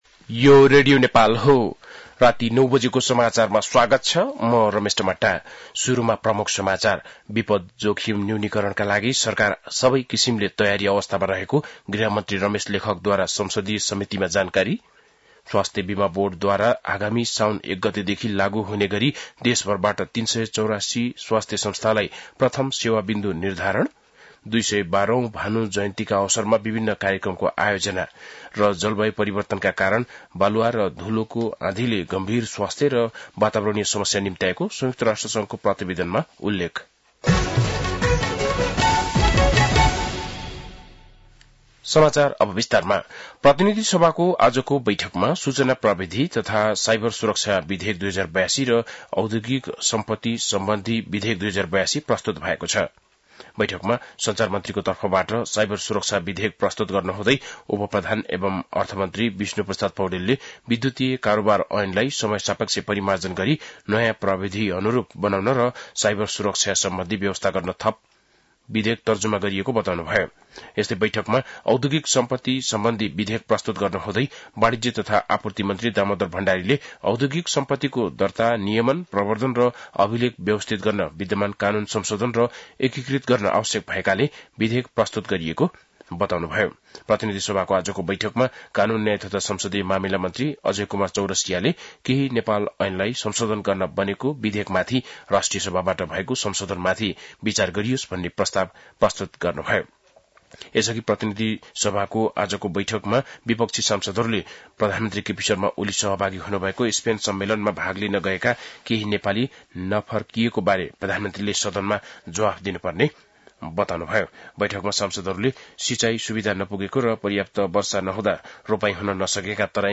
बेलुकी ९ बजेको नेपाली समाचार : २९ असार , २०८२
9-pm-nepali-news-3-29.mp3